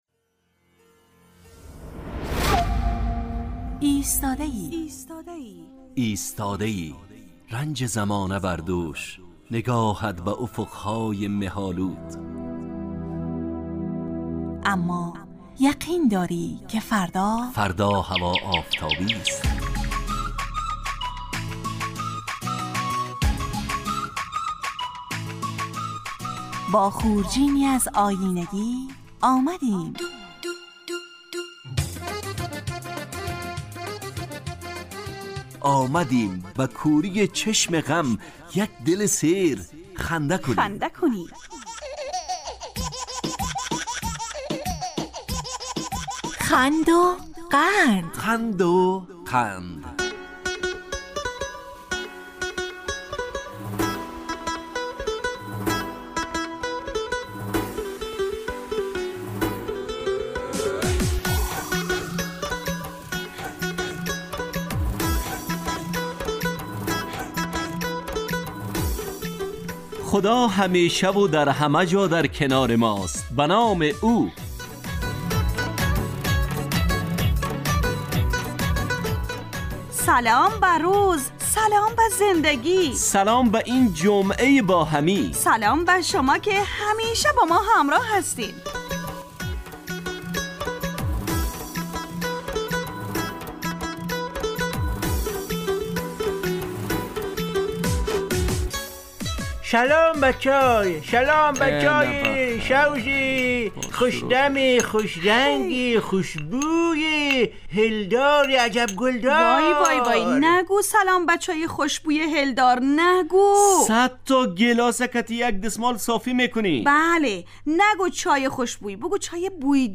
خند وقند برنامه ای طنز در قالب ترکیبی نمایشی است که هرجمعه به مدت 35 دقیقه در ساعت 9:15 به وقت ایران و 10:15 به وقت افغانستان از رادیو دری پخش میگردد.